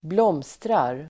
Uttal: [bl'åm:strar]